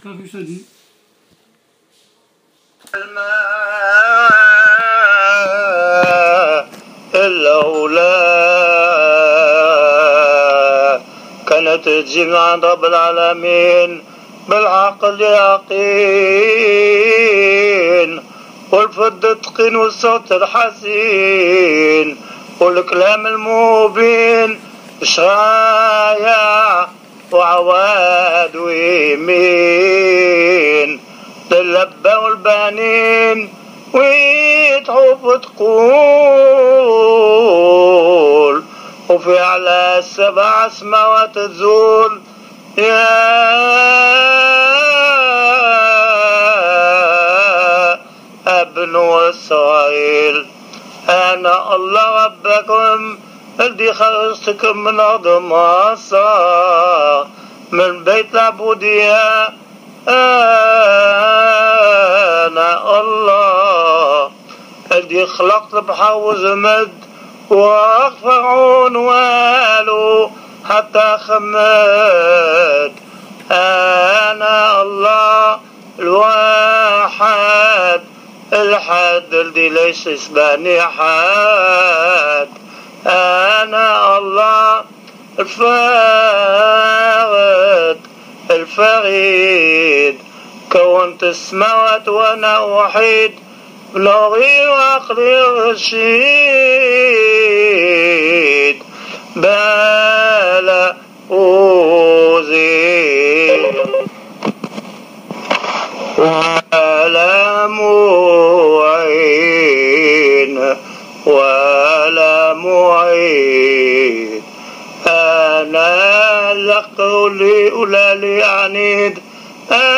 Les lectures des Dix Commandements